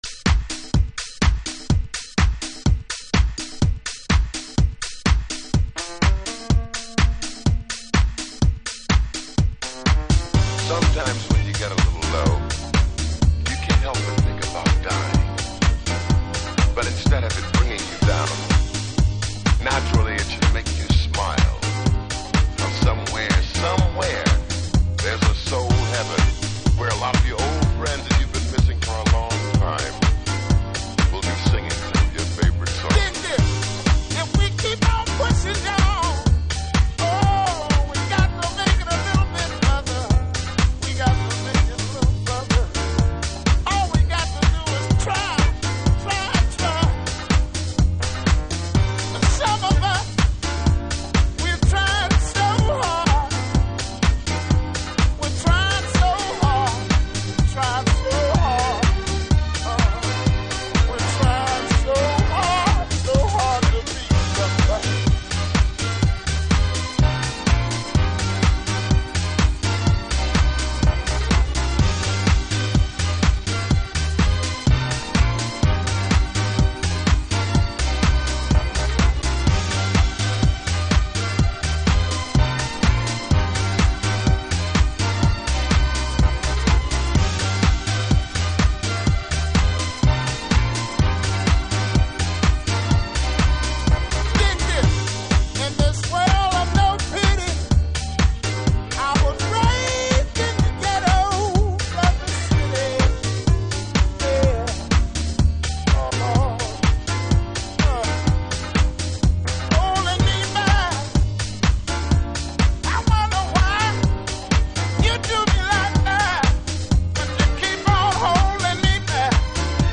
Early House / 90's Techno
チューニングなんて関係無い、大胆不敵のマッシュアップチューン。